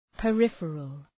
Προφορά
{pə’rıfərəl}